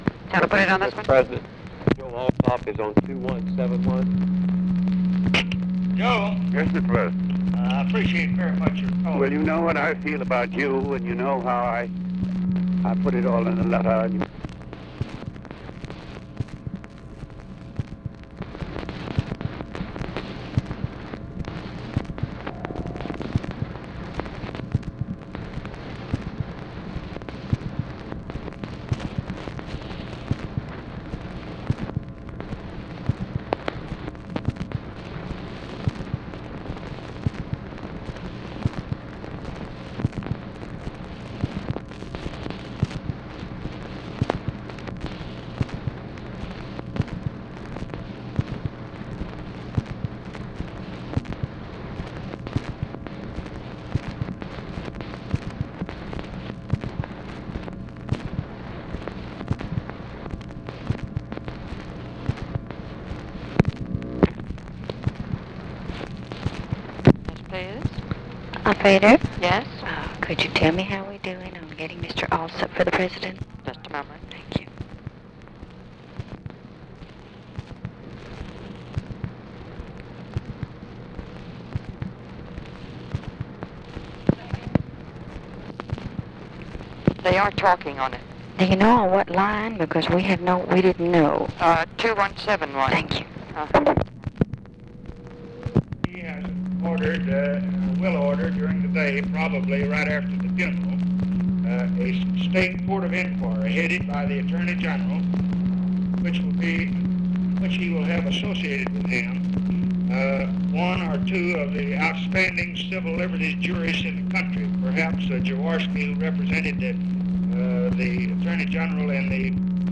Conversation with JOSEPH ALSOP, November 25, 1963
Secret White House Tapes